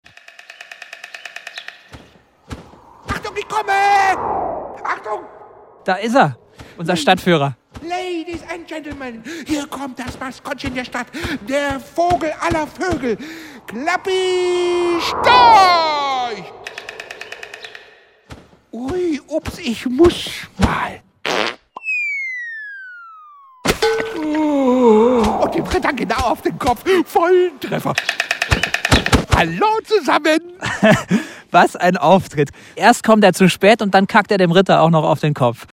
Macht euch gefasst auf seufzende Ritter, krächzende Reichsadler und einen sensationellen Storchen-Rap vor dem Tanzhaus! Natürlich erfahrt ihr auch alles rund ums Feuchtbiotop und das Nest der Donauwörther Storchenfamilie.